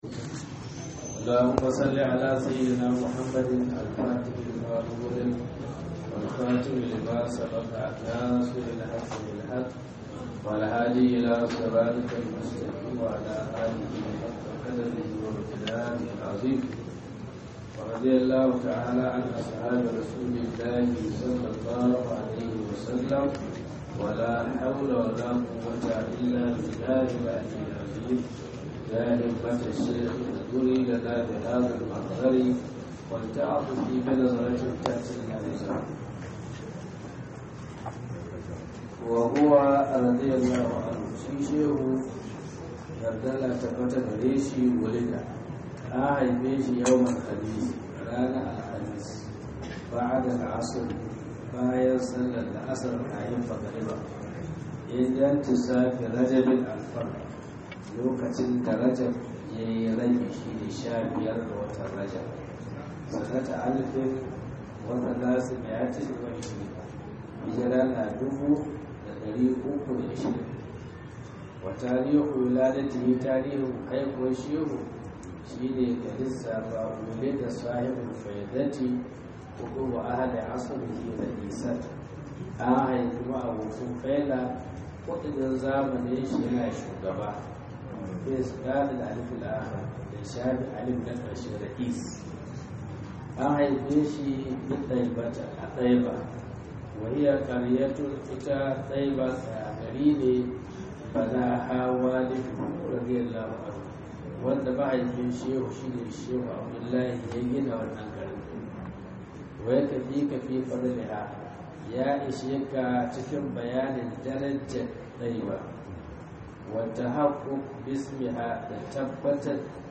Fityanumedia Audios is a platform dedicated to sharing audio files of lectures from renowned Islamic scholars.